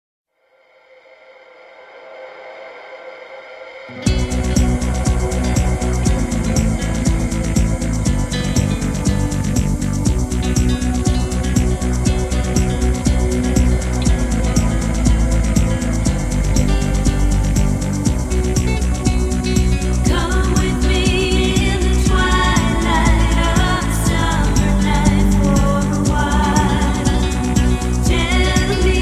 anime